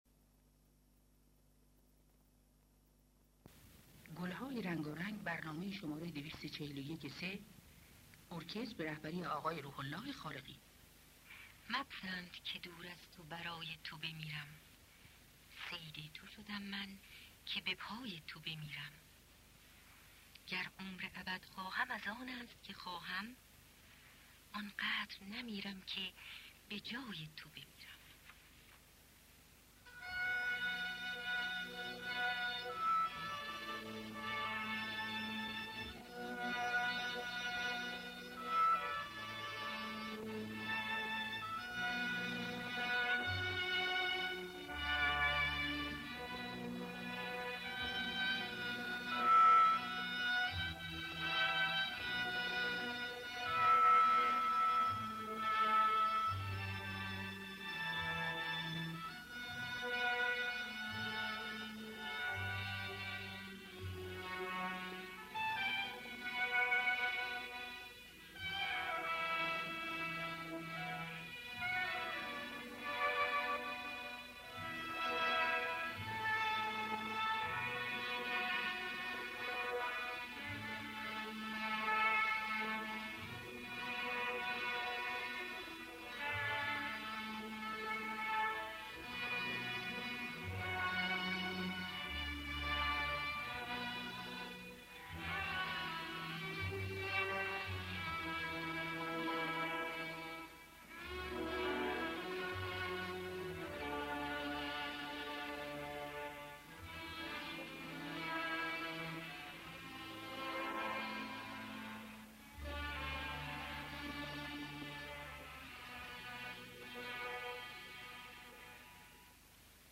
خوانندگان: الهه اکبر گلپایگانی